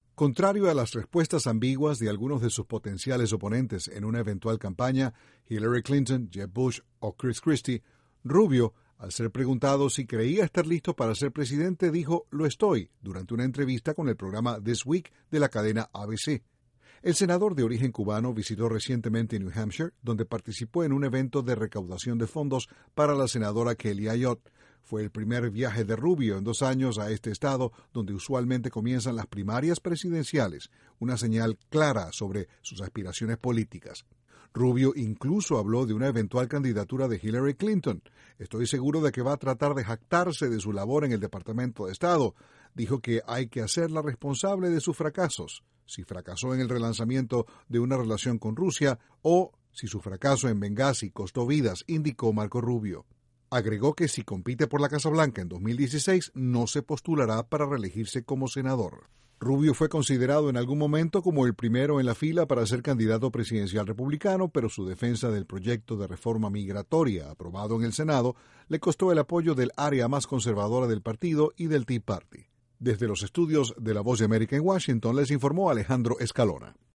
El senador republicano por el estado de la Florida, Marco Rubio, dijo en una entrevista tener aspiraciones de convertirse en el primer presidente hispano de Estados Unidos. Desde la Voz de América informa